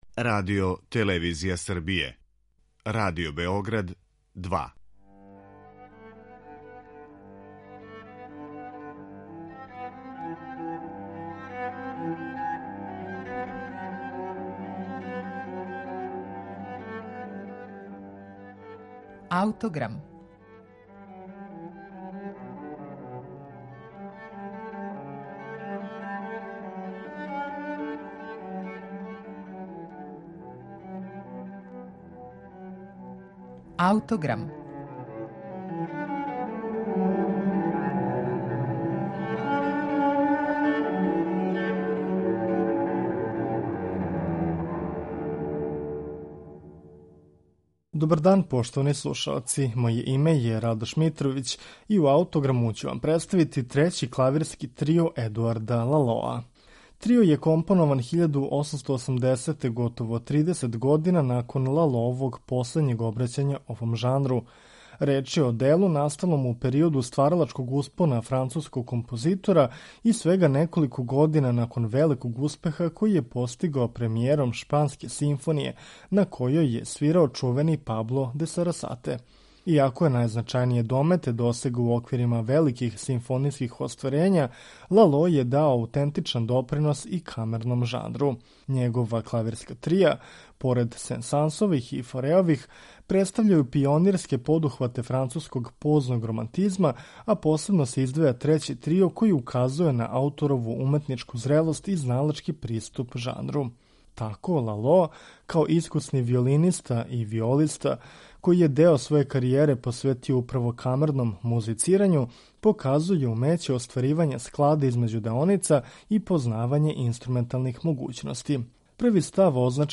Трећи клавирски трио Едуарда Лалоа слушаћемо у извођењу трија Парнасус.